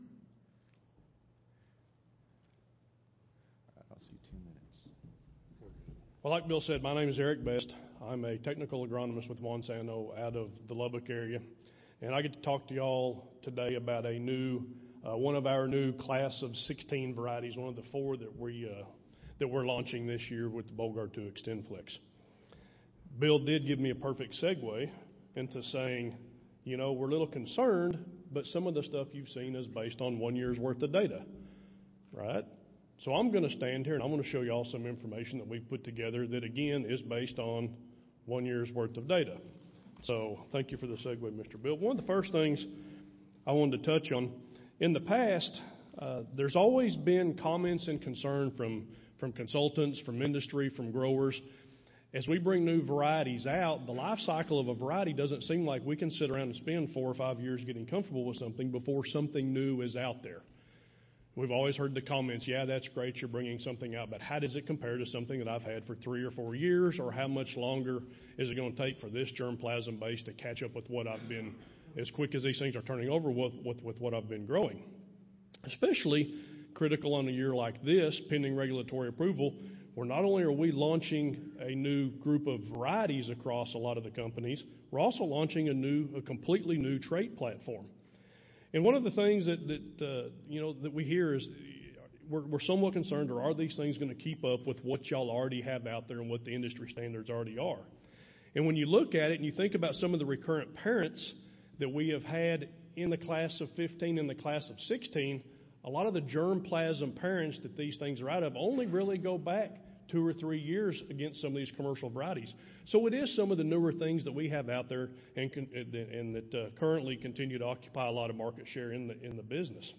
Mardi Gras Ballroom Salons A, B, C & D (New Orleans Marriott)
Recorded Presentation